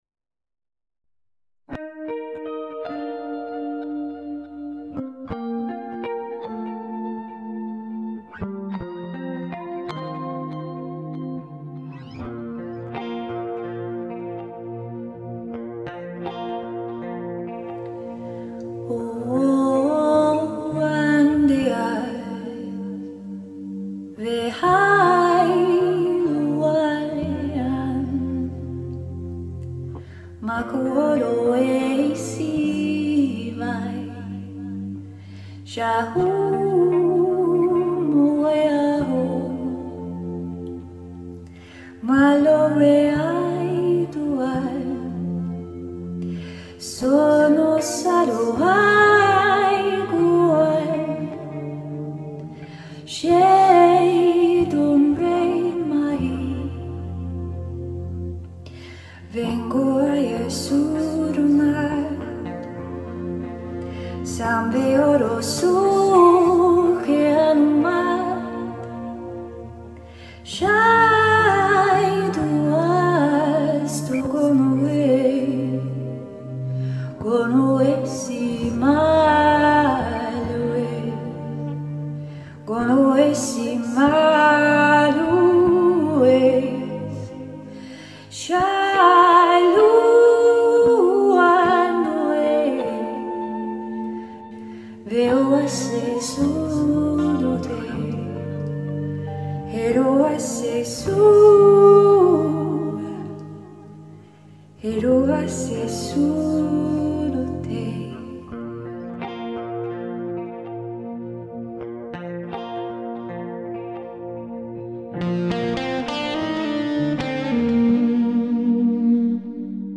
The links to songs below feature songs sung in light language, a language unique to its owner. With their permission I remotely recorded over the internet
singing acappella and then created a musical canvas behind them to complement their voices.